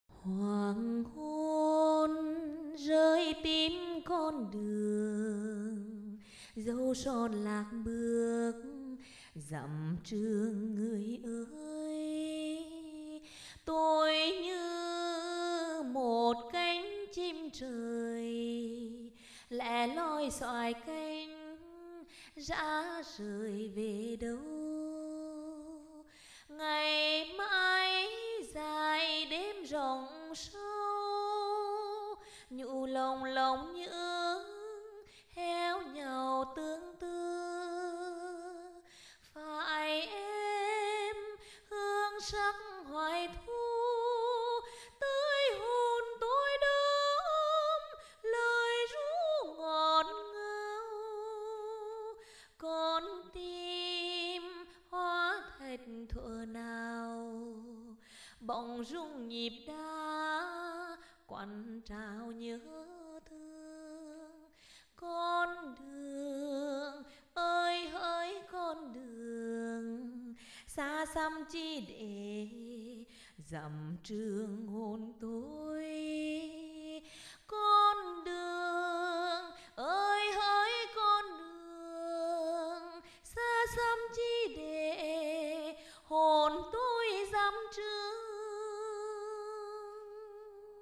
(Tải file nhạc Nữ: